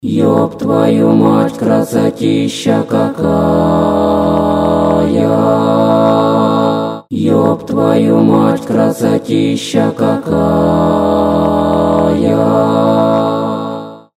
• Качество: 320, Stereo
позитивные
забавные
дуэт
хор
церковные
акапелла